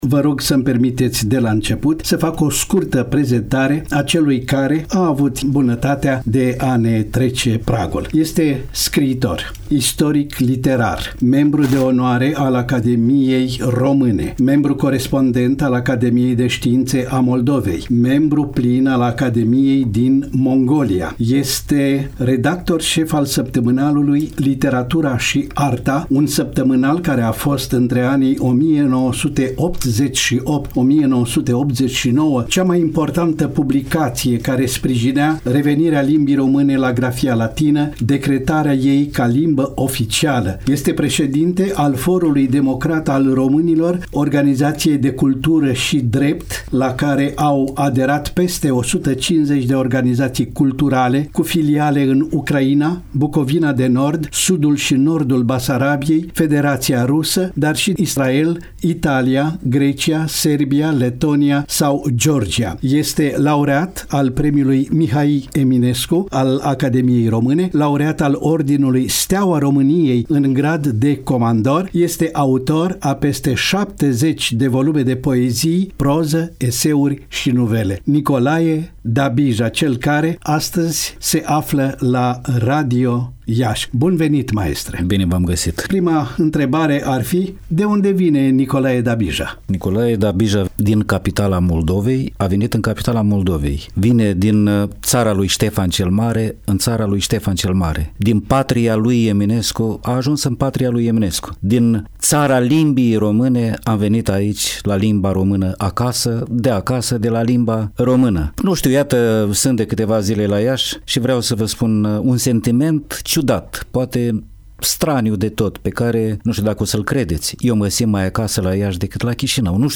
Unirea este inevitabilă - interviu cu academicianul Nicolae Dabija - Radio Iaşi – Cel mai ascultat radio regional - știri, muzică și evenimente